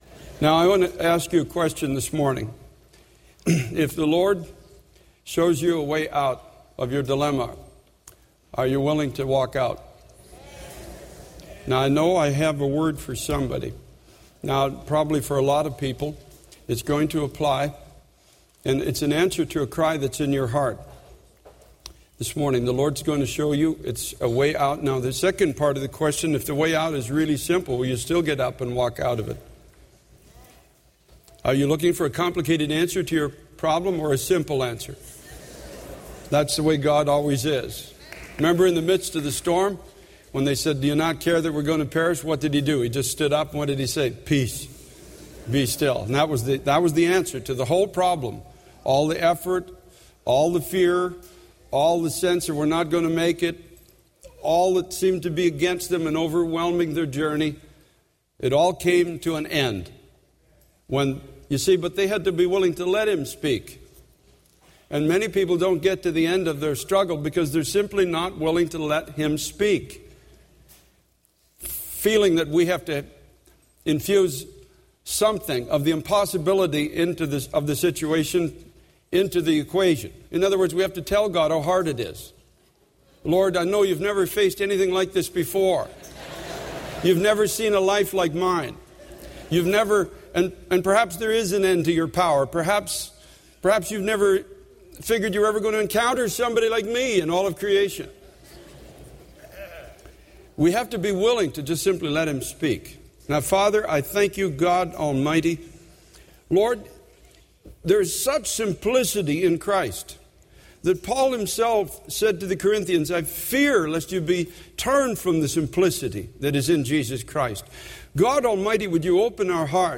This sermon emphasizes the importance of finding peace and strength in God during times of struggle and despair. It encourages listeners to trust in God's simplicity and power to provide a way out of difficult situations, reminding them to be willing to let God speak and bring peace.